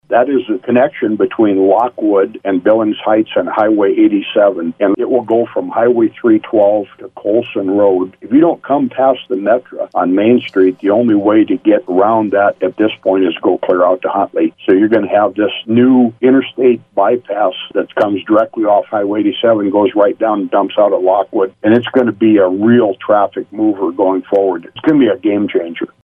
The Billings bypass project has been a work in progress for years but the end may be near, at least for the I-90 to highway 312 in the heights section. Yellowstone County Commissioner John Ostlund says he’s been told it might open July 4th. He explains what it is.